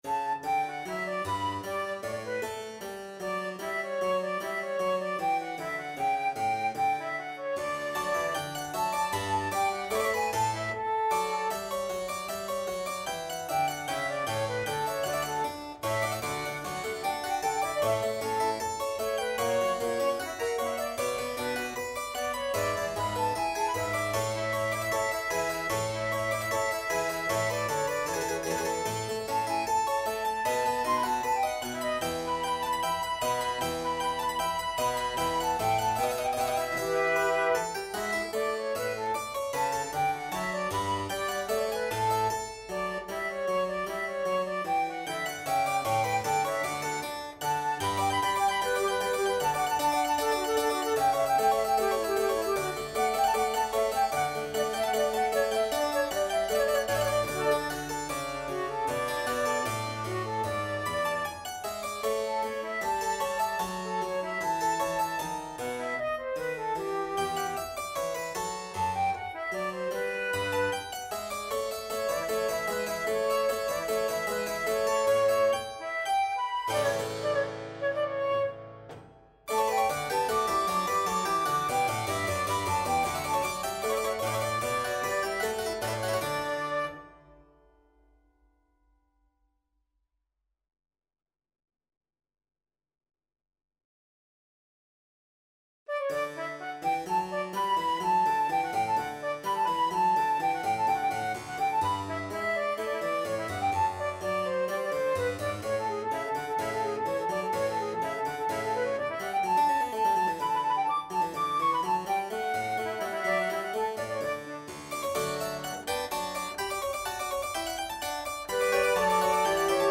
Classical
Multiple Soloists and Ensemble
concerto-for-flute-harpsichord-and-continuo-twv-42-d6.mp3